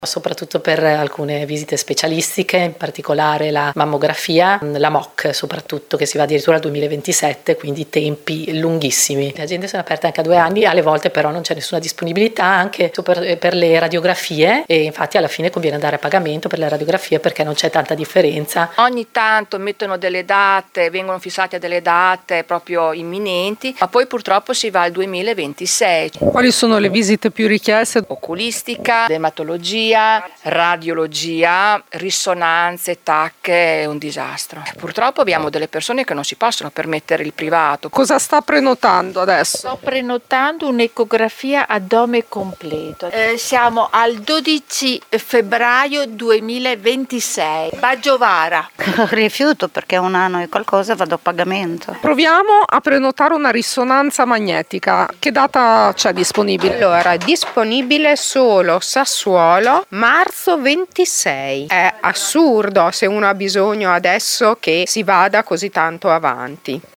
Siamo stati in alcune farmacie di Modena a verificare come sia la situazione: